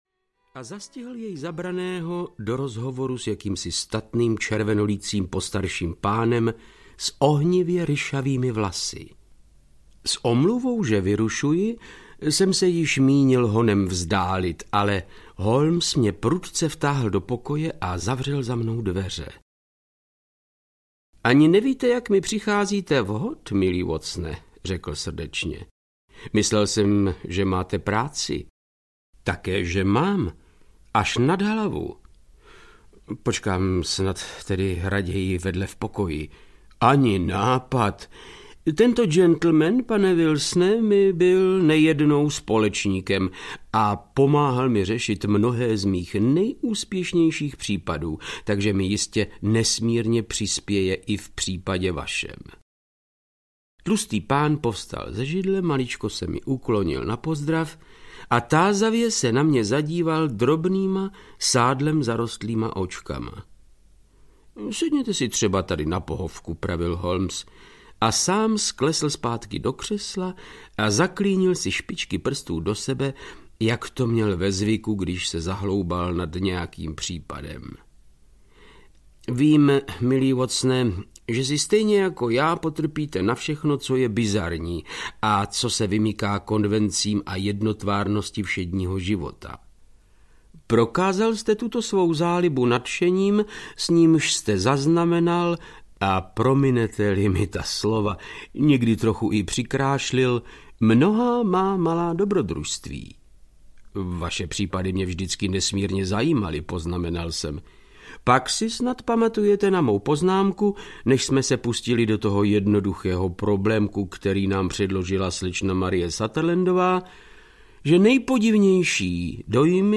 Spolek ryšavců audiokniha
Ukázka z knihy
• InterpretVáclav Knop